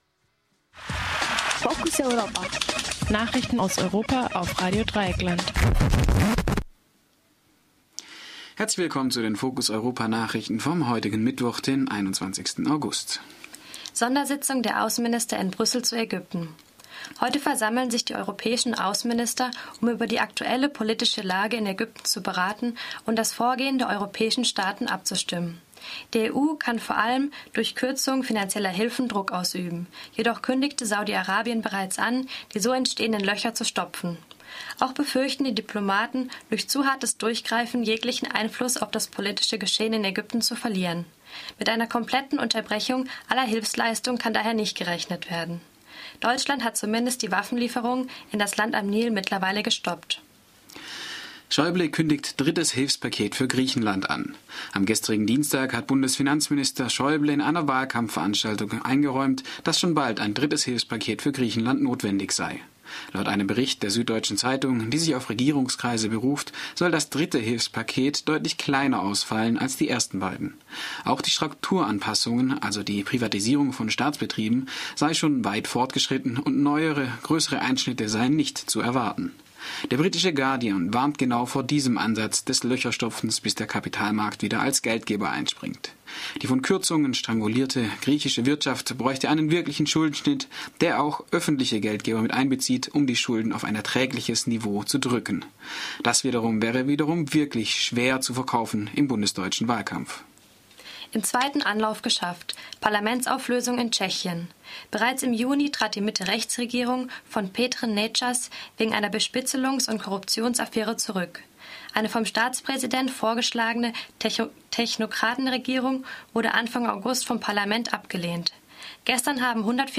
Focus Europa Nachrichten vom Mittwoch, den 21. August - 12:30